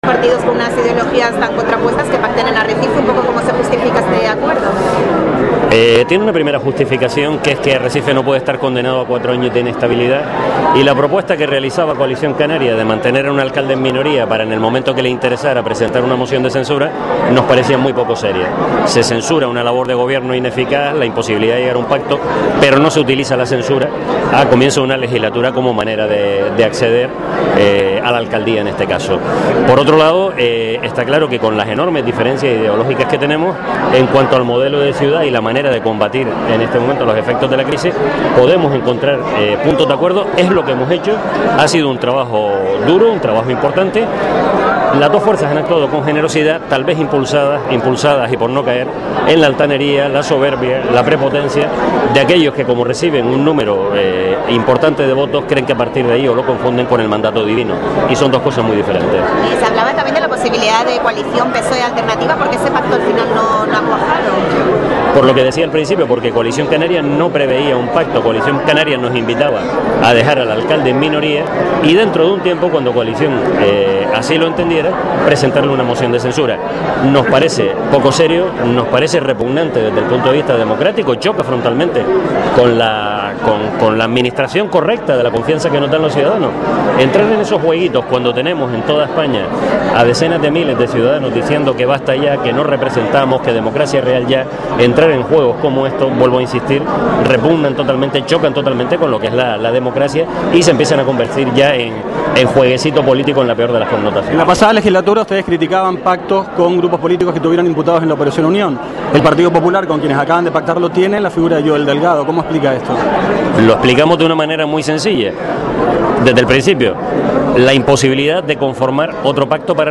Escuche aquí las declaraciones de Reguera, Montelongo, Fajardo Feo y Espino tras el pleno de Arrecife